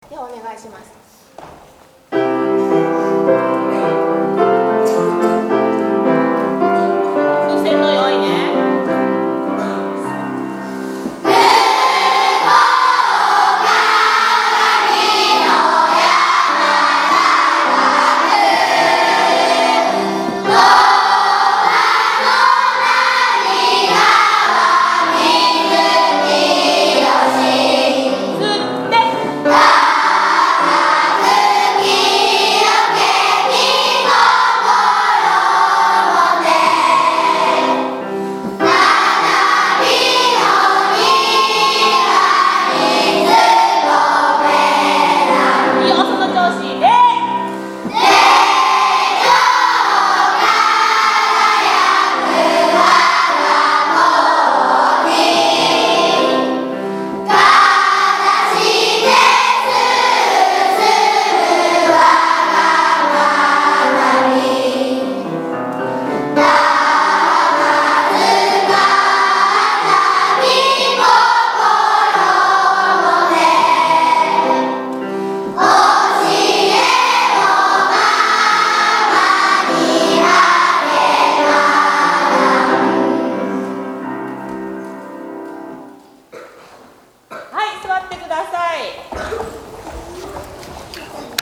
今朝の金曜集会は，本校の校歌の練習でした。
今朝は，子ども達の練習の様子を録音しましたので，どうぞお聞きください